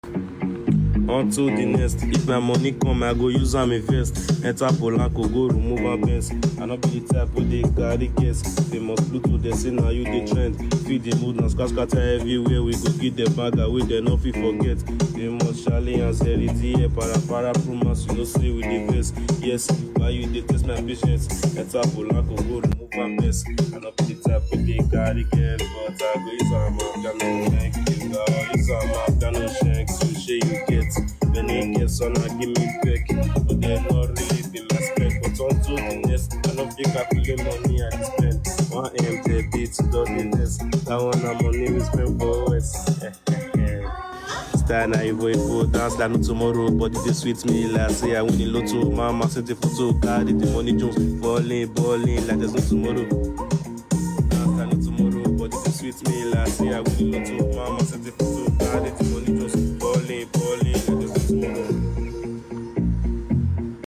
high vibe melody
Afrobeats